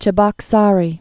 (chĭ-bŏk-särē)